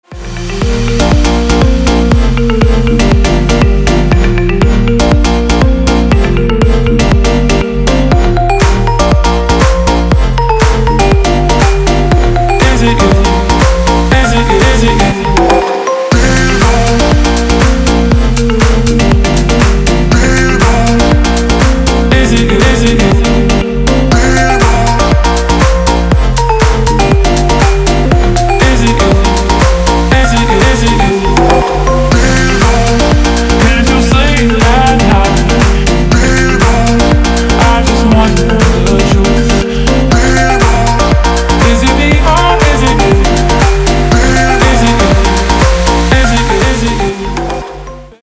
• Качество: 320, Stereo
мужской голос
громкие
deep house
мелодичные
Electronic
клавишные
летние
ксилофон